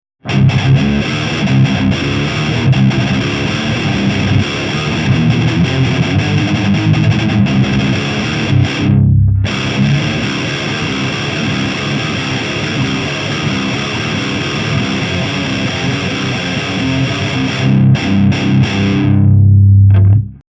サンプルはブースターとしての違い程度です。
Grass roots G-TN-58Gです。
JCM2000 DSL100
GAIN7 Bass8 Middle8 Treble7
マーシャル純正バルブ　トーンシフトON
ULTRA GAIN
LEED2